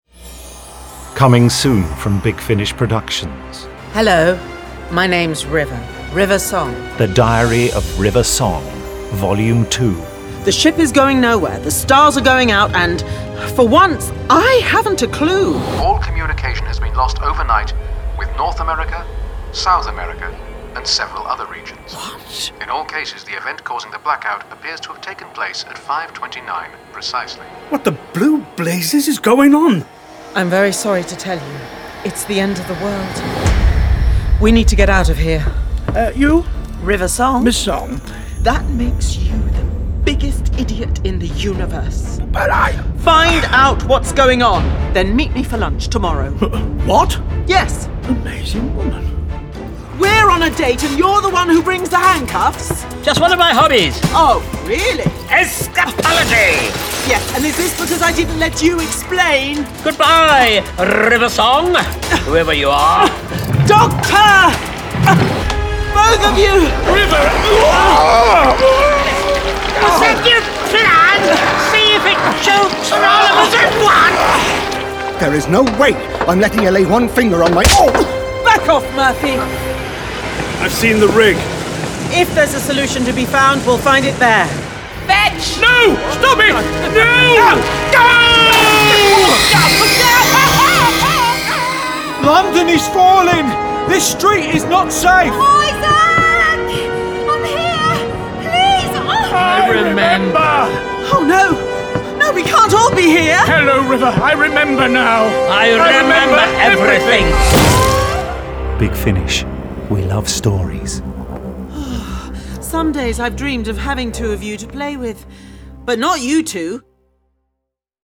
Award-winning, full-cast original audio dramas from the worlds of Doctor Who
Starring Alex Kingston Colin Baker